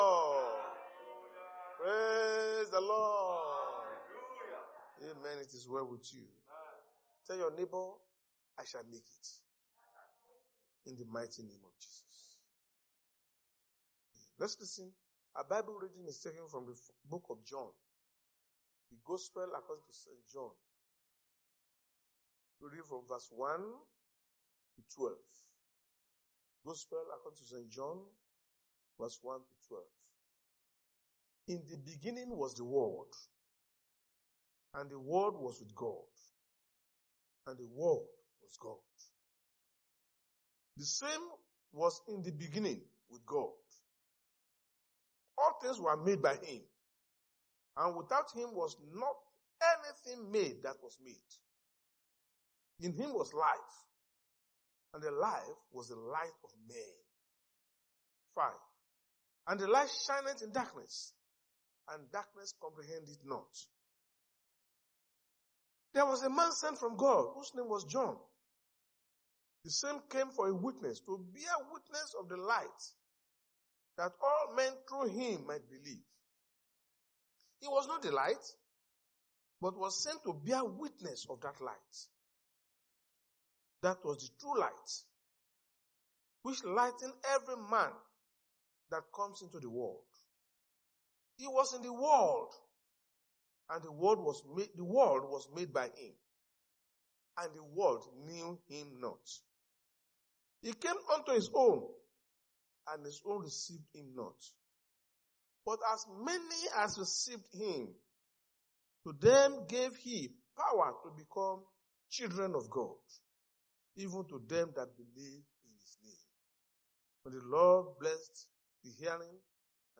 Sunday Sermon: Who Are You In Christ?
Service Type: Sunday Church Service